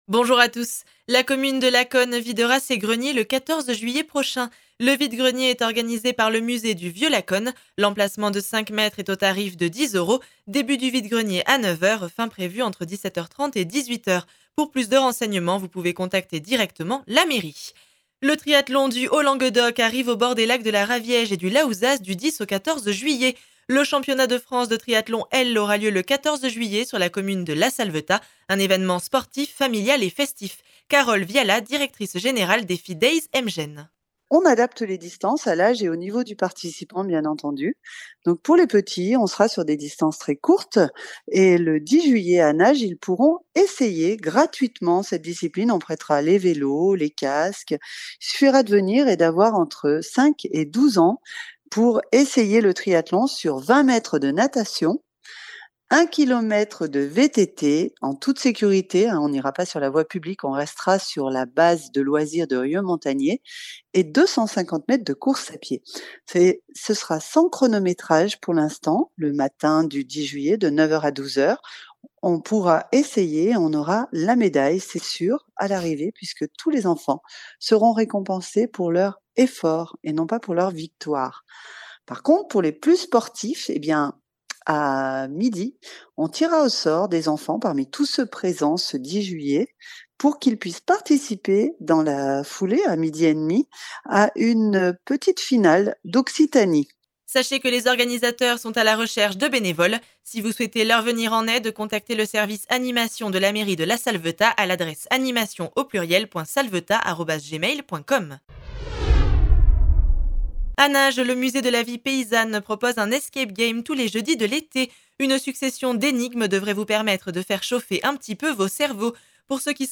Flash Monts de Lacaune 08 juillet 2022